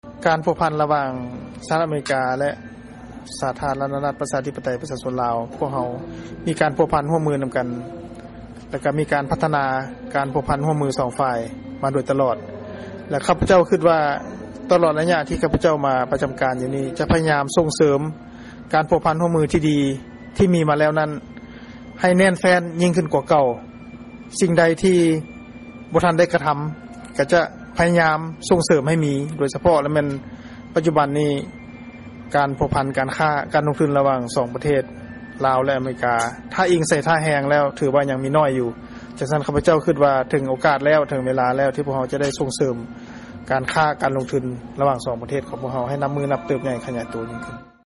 ຟັງການສຳພາດ ພະນະທ່ານ ເອກອັກຄະລັດຖະທູດ ໄມ ໄຊຍະວົງສ໌ ຈາກ ສປປ ລາວ 3